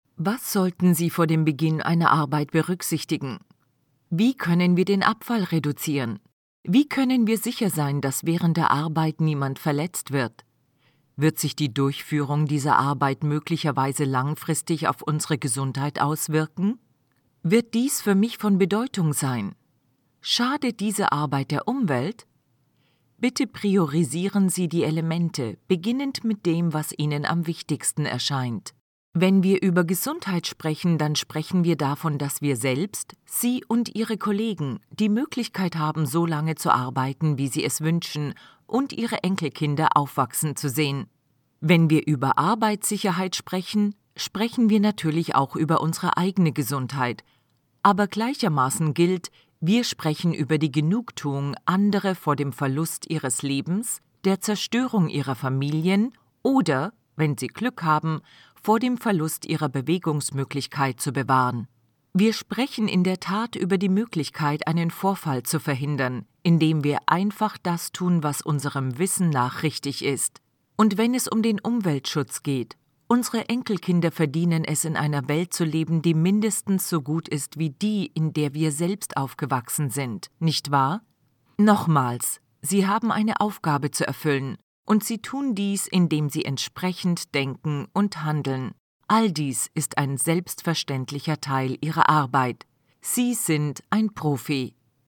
Female
German (Germany)
Adult (30-50)
E-Learning
Radio Commercials
All our voice actors have professional broadcast quality recording studios.